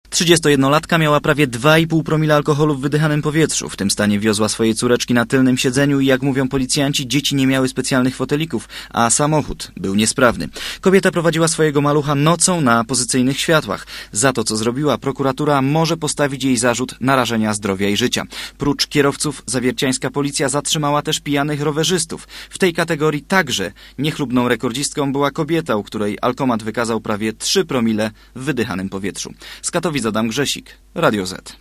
reportera Radia ZET